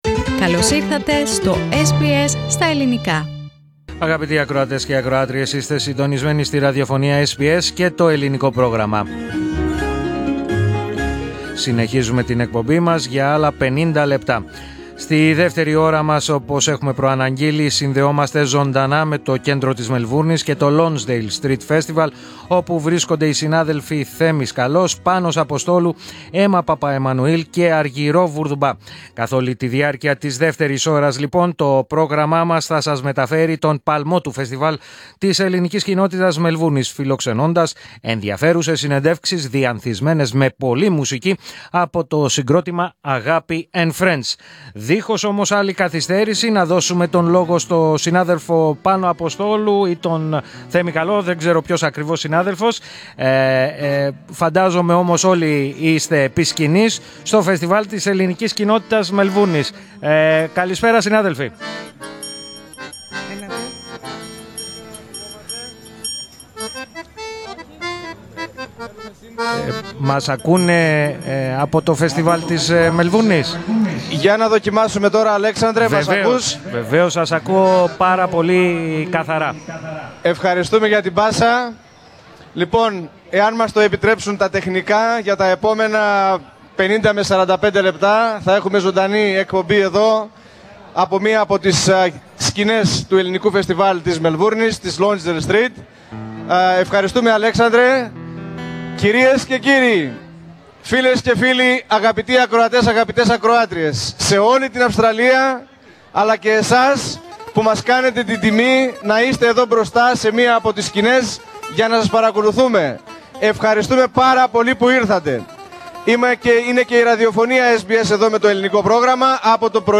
Ακούστε: Την ηχητική μετάδοση του Ελληνικού Προγράμματος από το Lonsdale Greek Festival
Δεν είχατε την ευκαιρία να ακούσετε την απευθείας μετάδοση του Ελληνικού Προγράμματος της ραδιοφωνίας SBS από το 32o Lonsdale Street Greek Festival; Ακούστε την εδώ.
Ακούστε ολόκληρη την εκπομπή του Ελληνικού Προγράμματος της Ραδιοφωνίας SBS από το 32ο Lonsdale Street Greek Festival της Μελβούρνης.